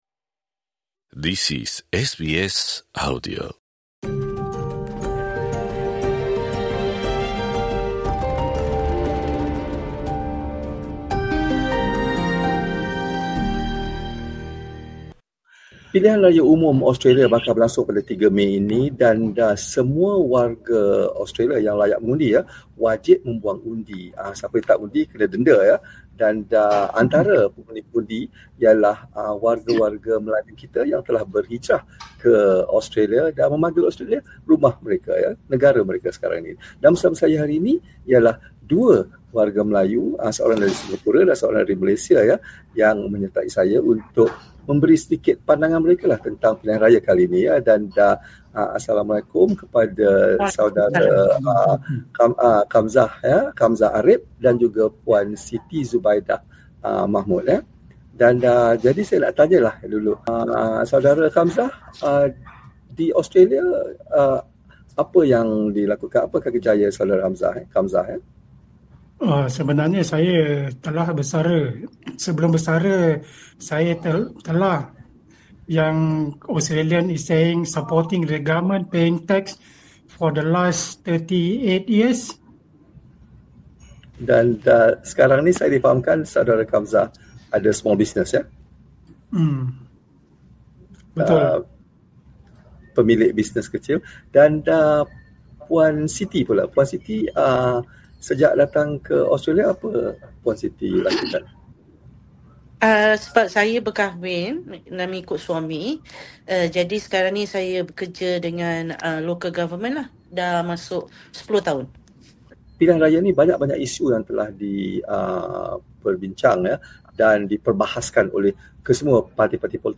SBS Bahasa Melayu menemubual dua warga Melayu, seorang dari Malaysia dan seorang lagi dari Singapura, untuk meninjau apakah isu utama yang akan memancing undi mereka kali ini.